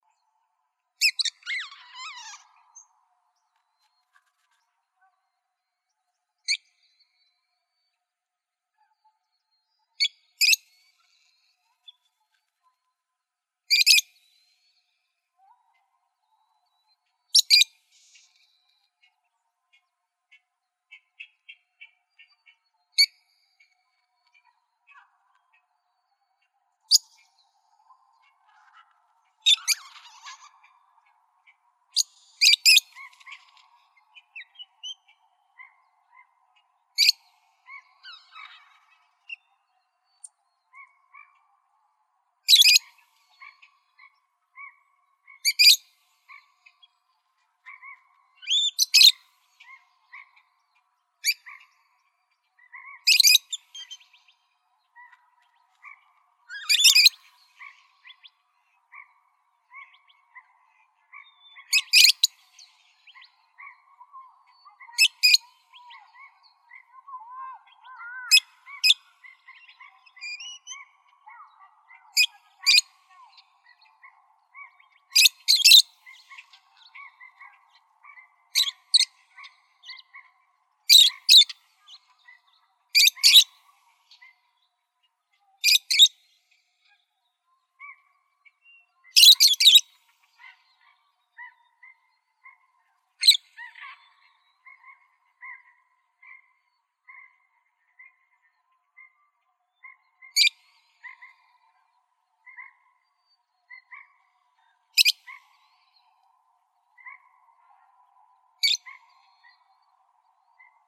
rosyfacedlovebird.wav